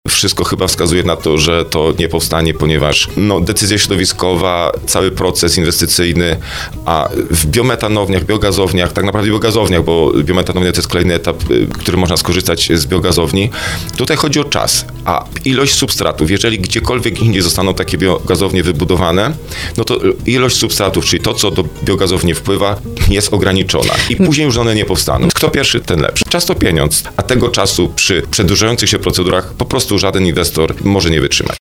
Wójt gminy Skrzyszów, Marcin Kiwior, na antenie RDN Małopolska przyznał, że wszystko wskazuje na to, iż planowana biometanownia na terenie gminy nie powstanie.